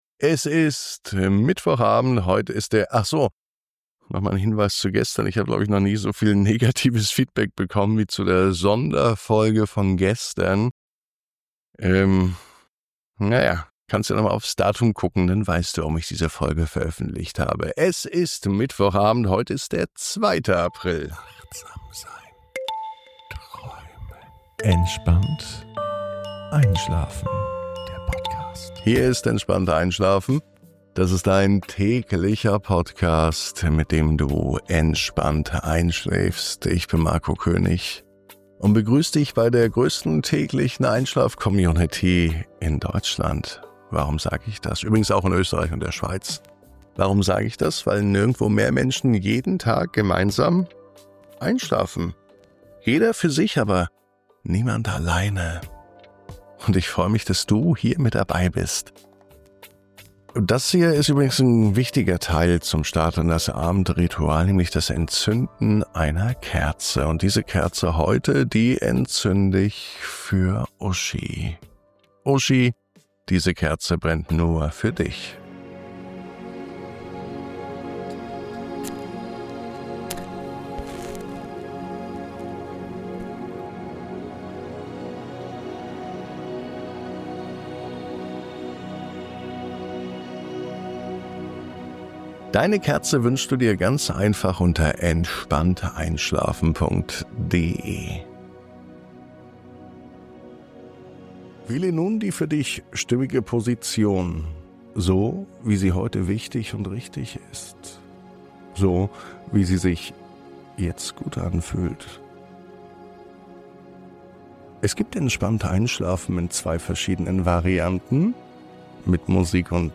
Meditation & Achtsamkeit für die Nacht